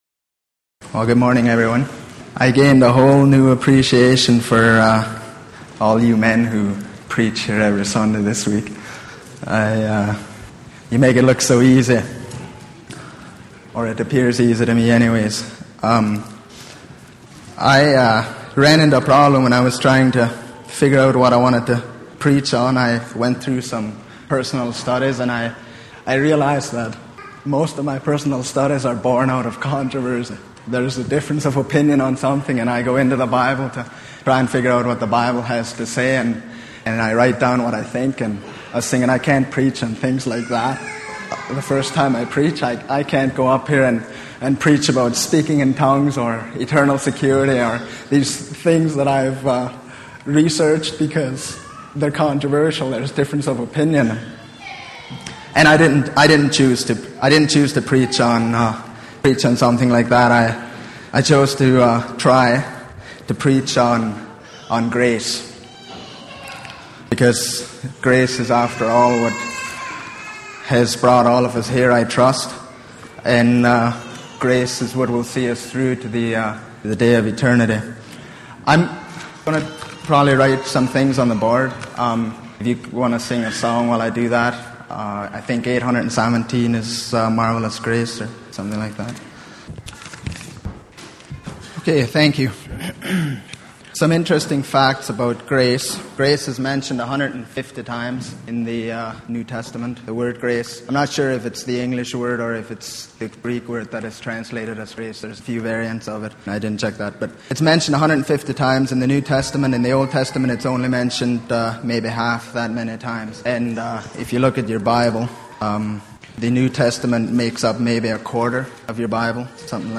Sunday Morning Sermon Passage: John 1:1-18 Service Type